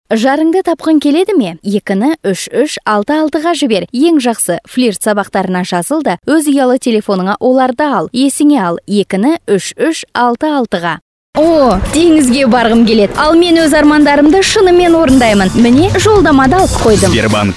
Kazakça Seslendirme
KADIN SESLER